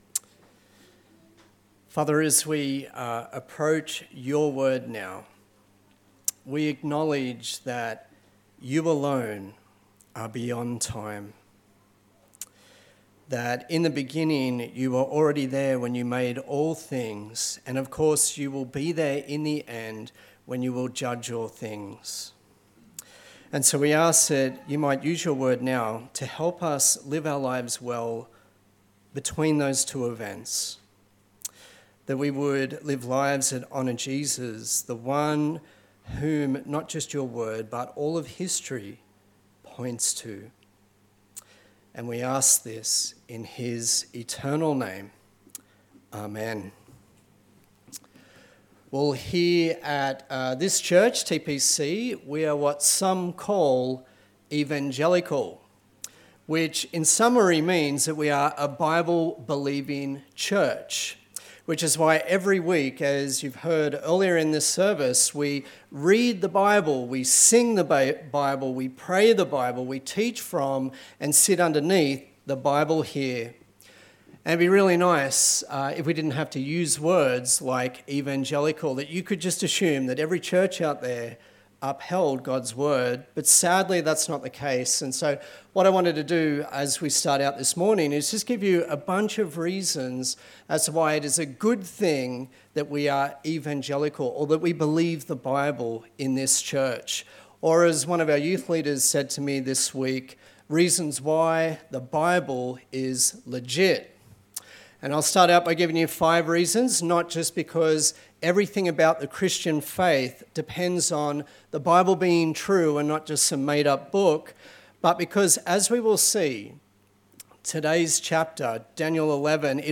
Daniel Passage: Daniel 11 Service Type: Sunday Service